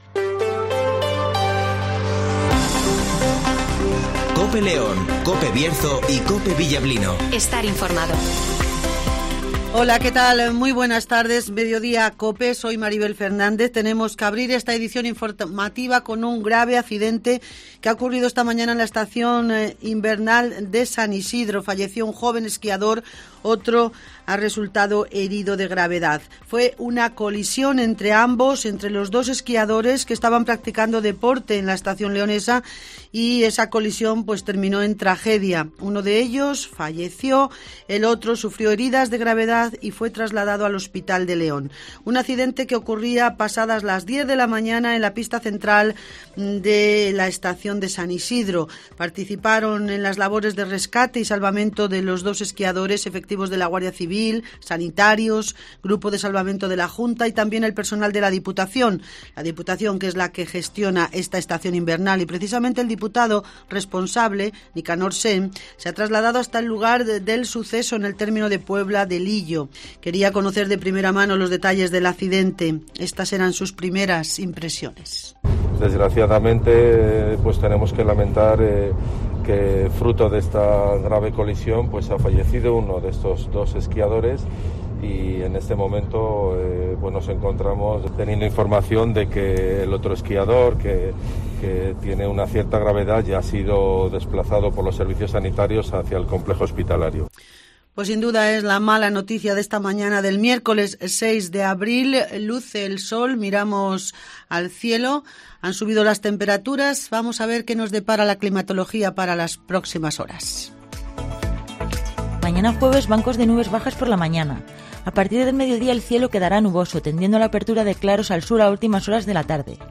- Nicanor Sen ( Diputado de Turismo )